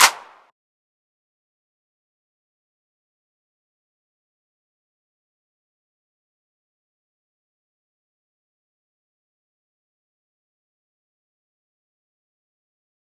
Clap (HallofFame).wav